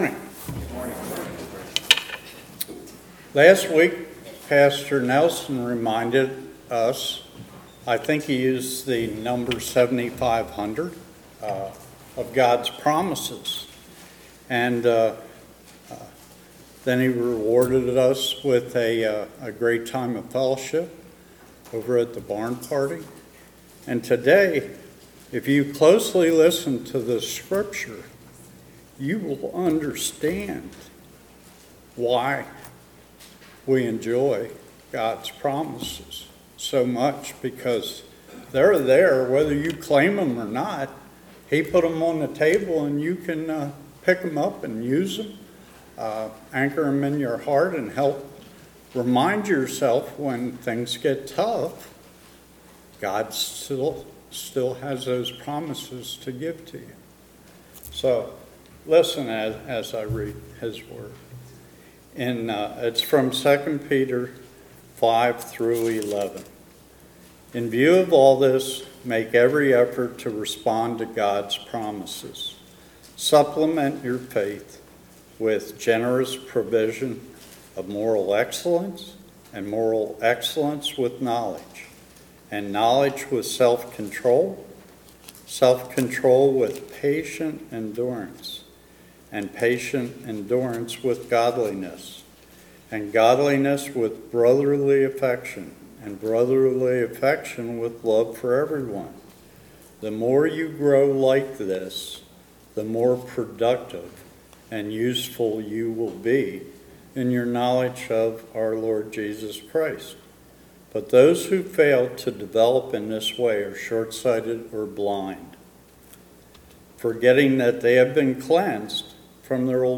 A message from the series "October 2025."